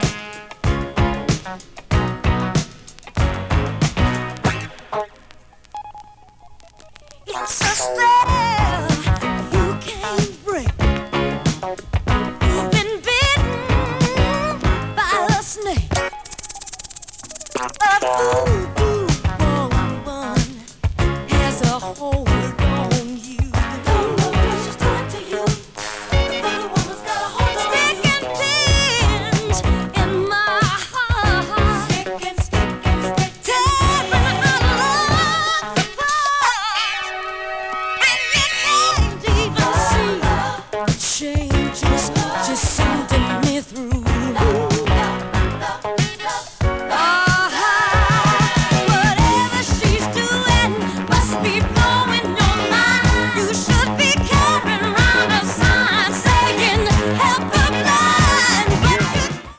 70s MELLOW SOUL / FUNK / DISCO 詳細を表示する